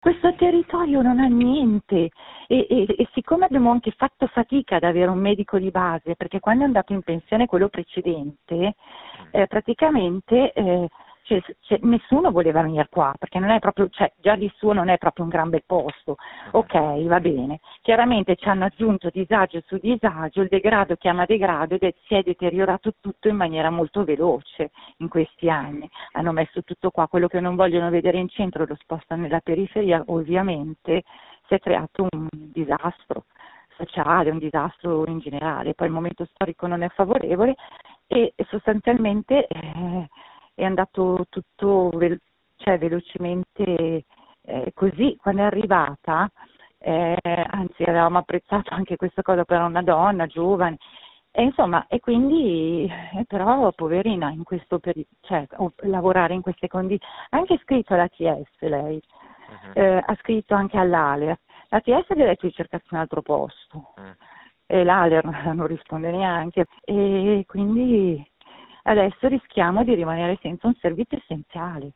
È sua la voce che racconta come si vive nella periferia dimenticata di una grande città.